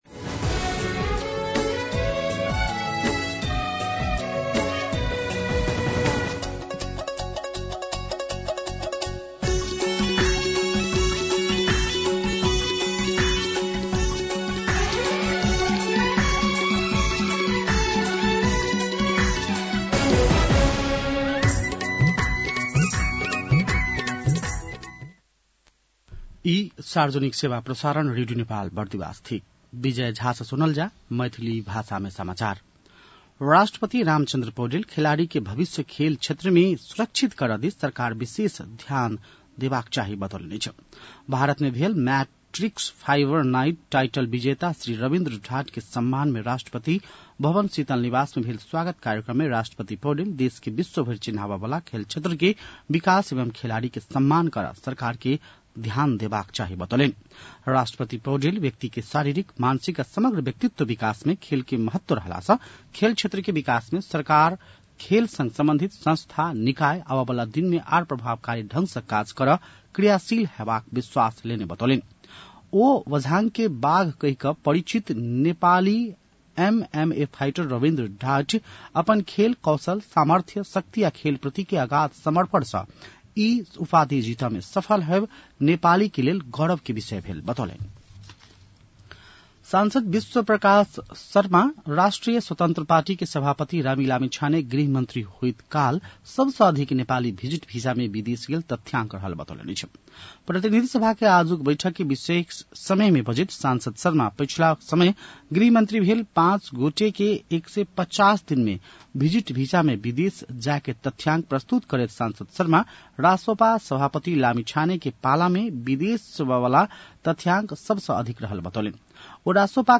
मैथिली भाषामा समाचार : २१ साउन , २०८२